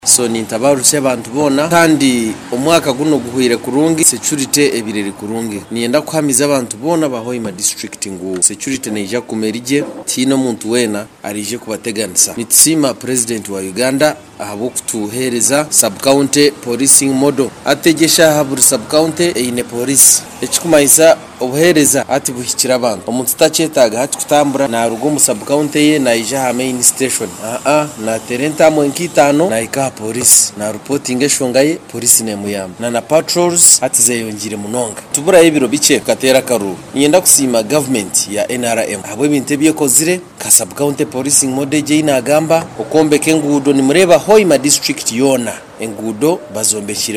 Rogers made this call while in an interview with our reporter in Hoima District.